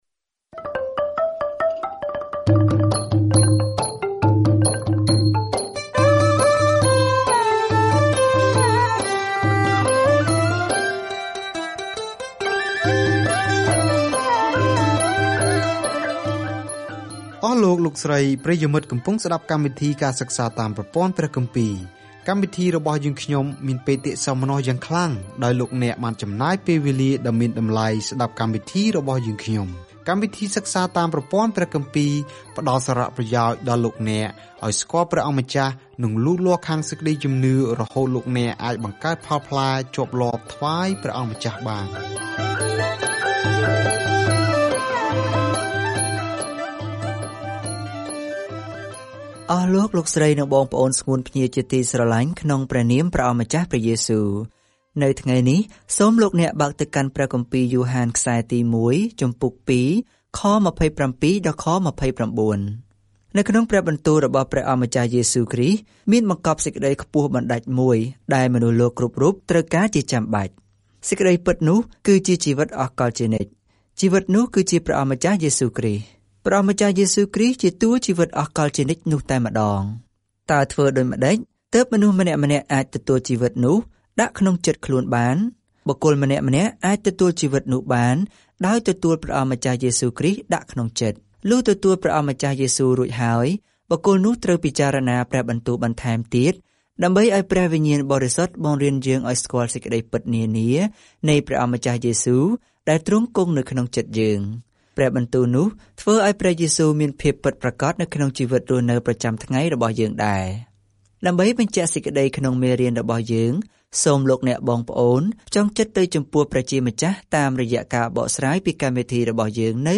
មិនមានមូលដ្ឋានកណ្តាលនៅក្នុងសំបុត្រទីមួយនេះពី John - ទាំងយើងជ្រើសរើសពន្លឺឬភាពងងឹត, ការពិតទៅកុហក, ស្រឡាញ់ឬស្អប់; យើងឱបក្រសោបមួយ ឬមួយទៀត ដូចជាយើងជឿ ឬបដិសេធព្រះអម្ចាស់យេស៊ូវគ្រីស្ទ។ ការធ្វើដំណើរប្រចាំថ្ងៃតាមរយៈ យ៉ូហានទី 1 នៅពេលអ្នកស្តាប់ការសិក្សាជាសំឡេង ហើយអានខគម្ពីរដែលជ្រើសរើសចេញពីព្រះបន្ទូលរបស់ព្រះ។